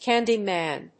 /ˈkændimæn(米国英語), ˈkændi:mæn(英国英語)/